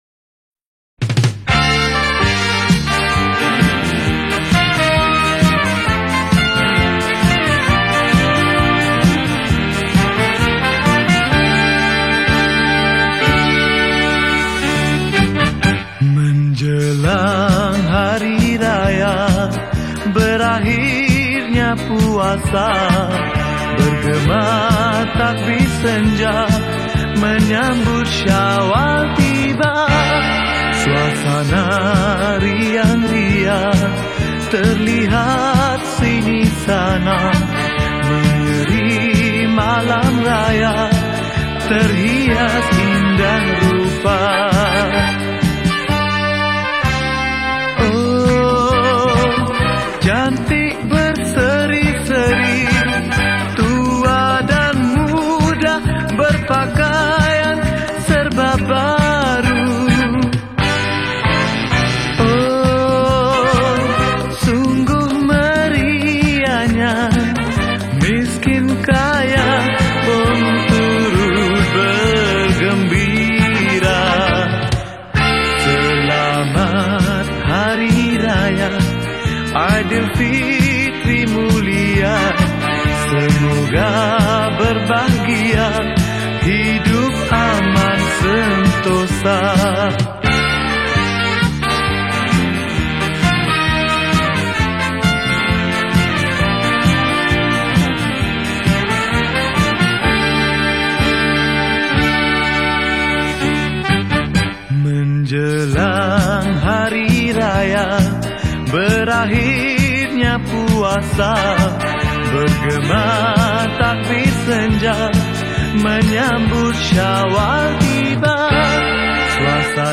Skor Angklung